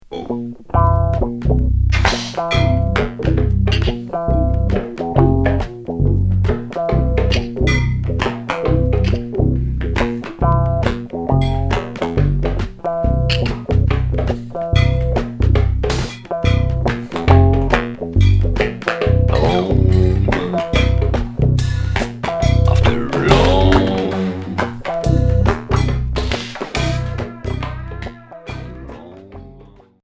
Bruitiste expérimental Unique 45t retour à l'accueil